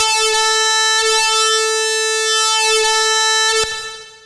gtdTTE67009guitar-A.wav